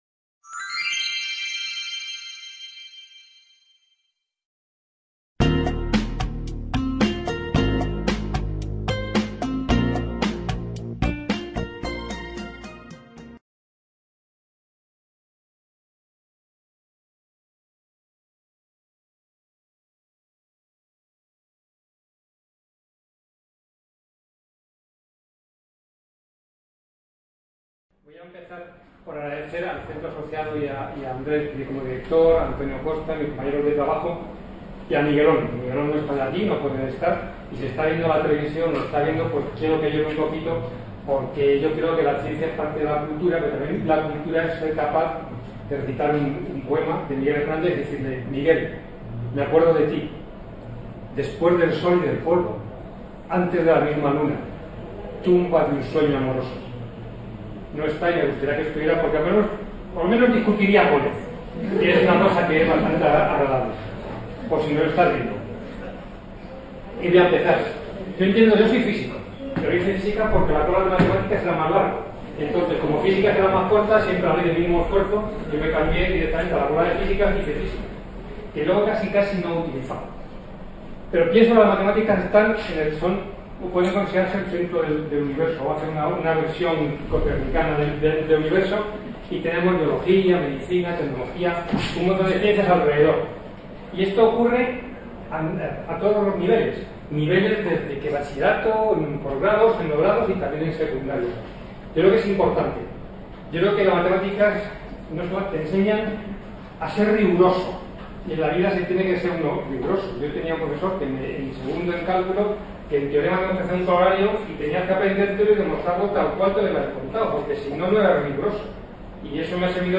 Estas Jornadas pretenden, como ya lo hiciese en los dos últimos años 2016 y 2017, ser un punto de encuentro para compartir experiencias educativas reales en Estadística y/o Matemáticas que se enmarquen en el ámbito cotidiano de la docencia en los diferentes niveles de la educación Matemática aunque está abierto a docentes de otras materias.